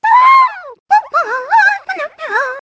One of Wiggler's voice clips in Mario Kart 7